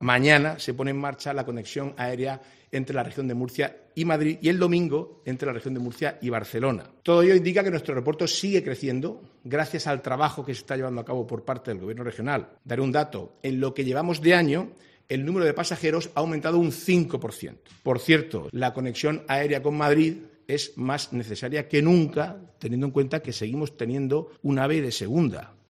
Marcos Ortuño, consejero de Presidencia y Portavocía
En una rueda de prensa tras el Consejo de Gobierno, Ortuño ha celebrado que este viernes se inicie la conexión aérea entre la Región y Madrid y el domingo con Barcelona.